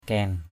kain.mp3